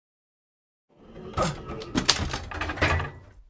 Wajcha_wood.ogg